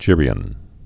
(jîrē-ən, gĕr-)